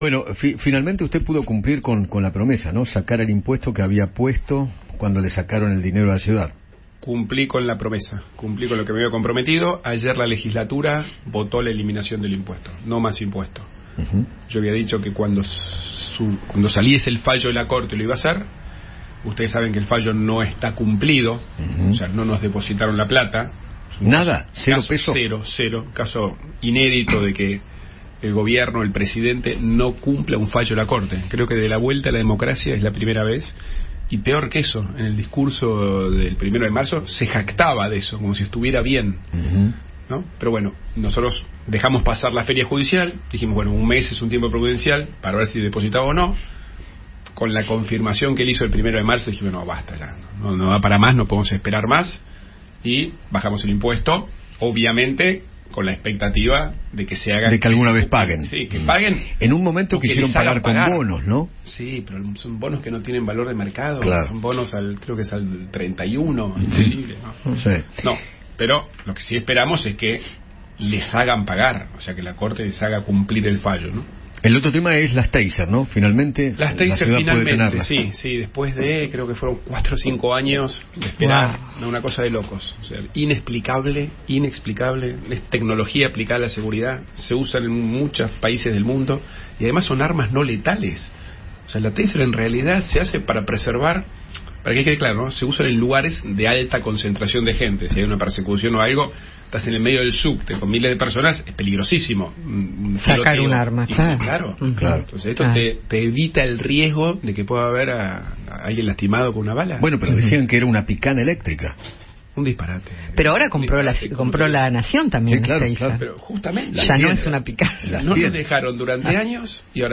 Horacio Rodríguez Larreta, jefe de Gobierno porteño, visitó los estudios de Radio Mitre y conversó con Eduardo Feinmann de cara a las Elecciones 2023.